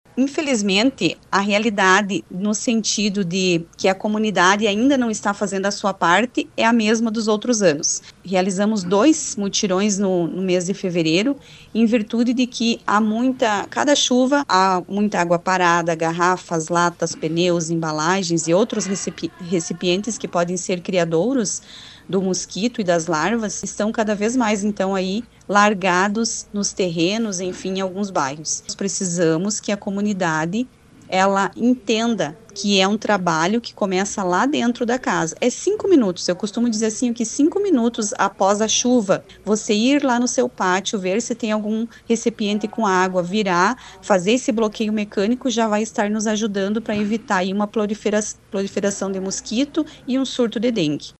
O assunto foi ampliado, hoje, das 18 às 19 horas, no programa de Pejuçara, pela RPI, durante entrevista com a secretaria Eliana. Na ocasião, ela também falou sobre o trabalho de combate à dengue no município.